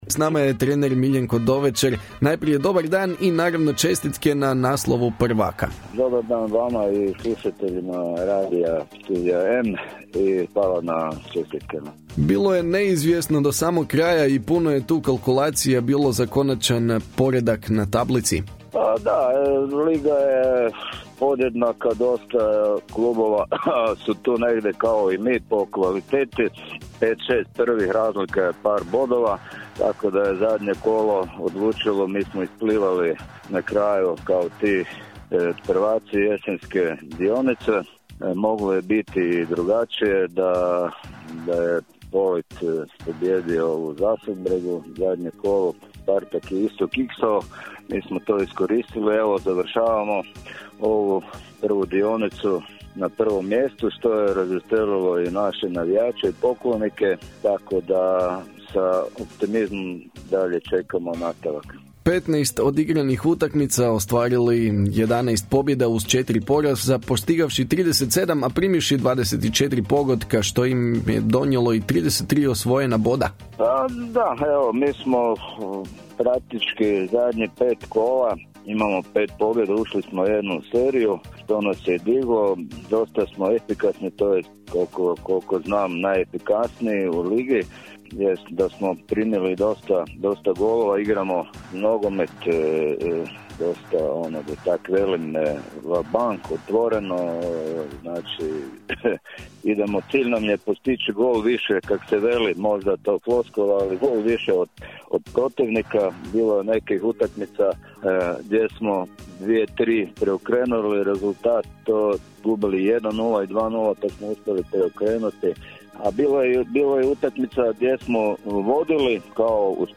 Donosimo intervju iz naše Zone sporta.